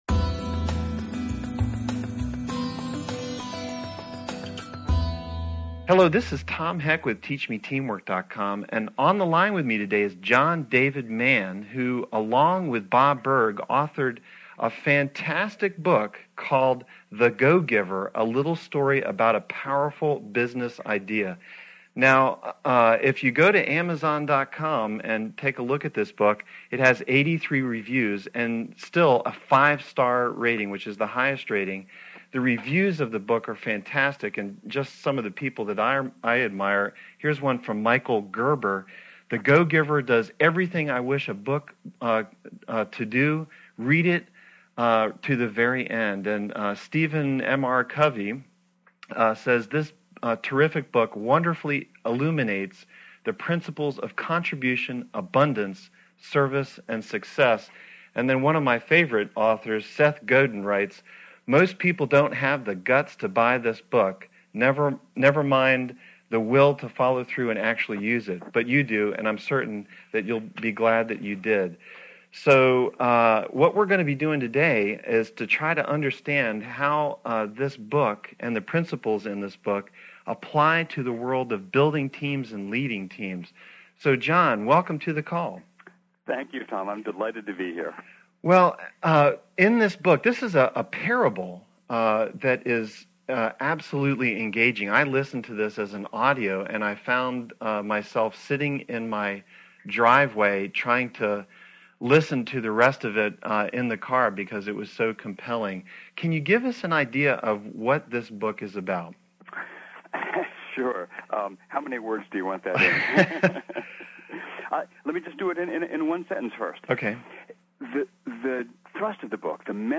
How to achieve individual and team success using principles from “The Go-Giver” — an audio interview